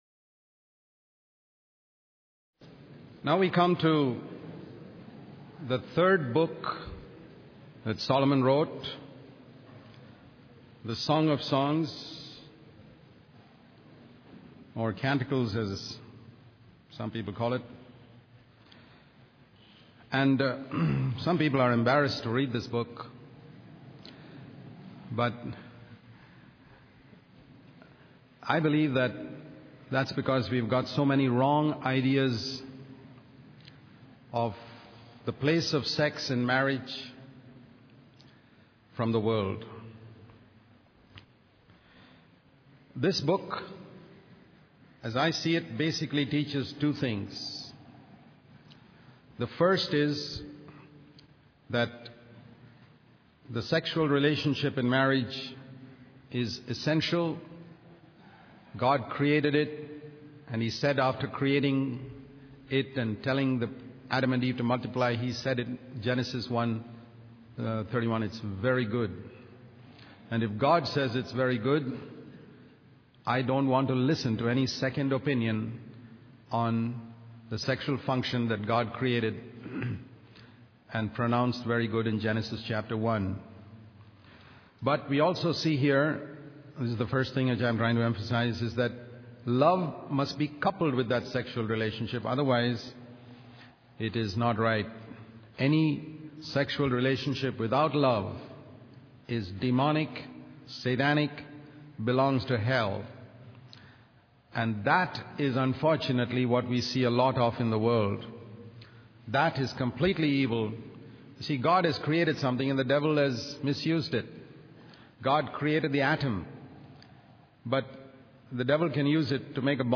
In this sermon, the speaker emphasizes the importance of finding godly older people who can guide and teach us in our devotion to Jesus Christ.